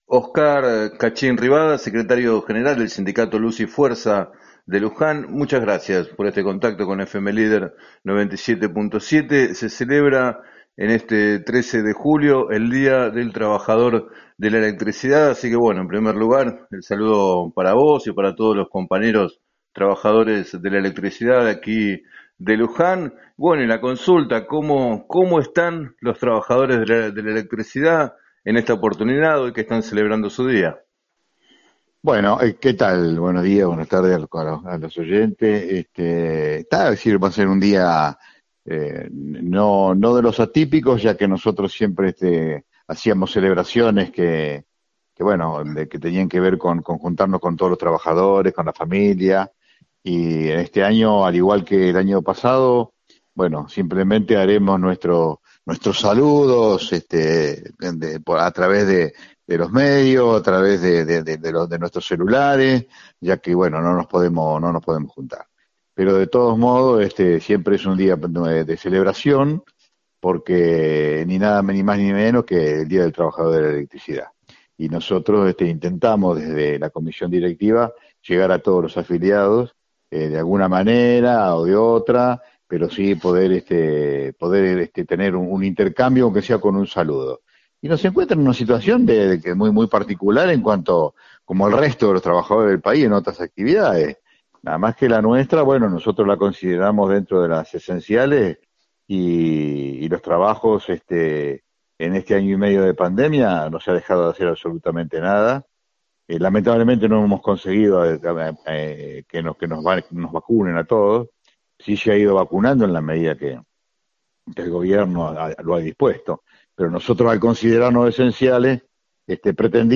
En declaraciones al programa “7 a 9” de FM Líder 97.7